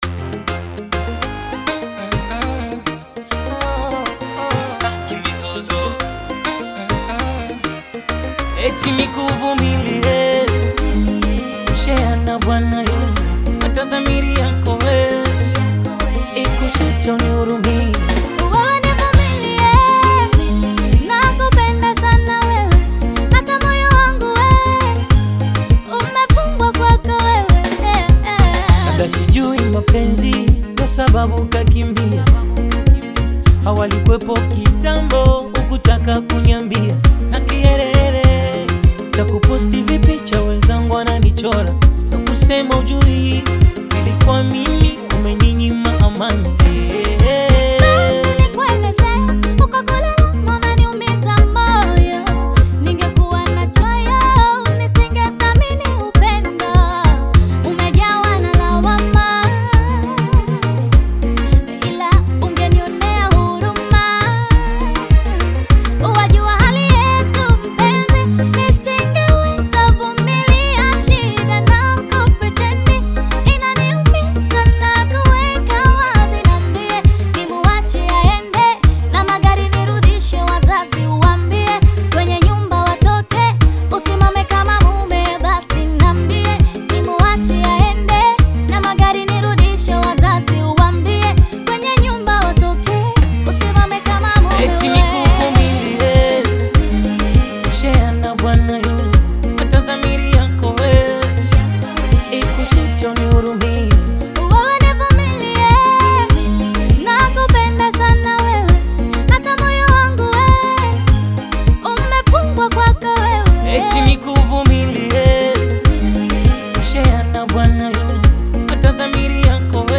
infectious collabo